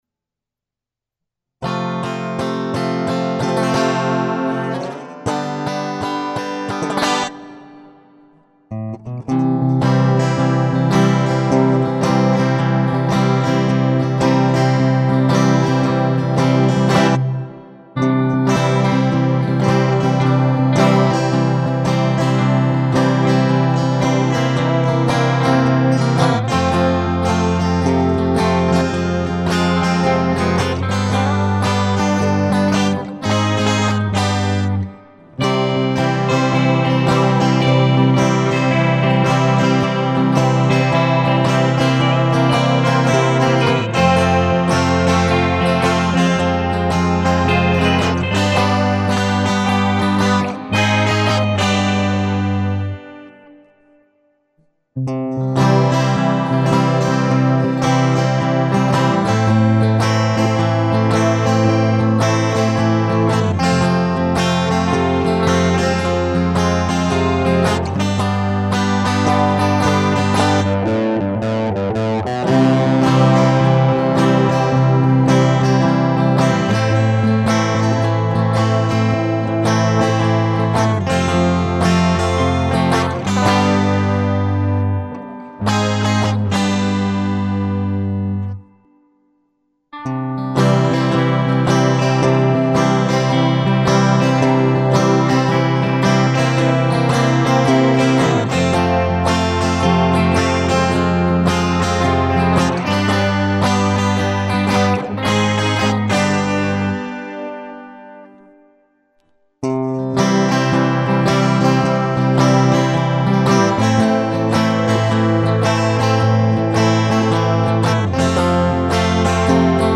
музыка гитара блюз облезлого кота
Пока без слов, только музычка. Играл на гитаре, по очереди все дорожки. Итого получилось девять дорожек.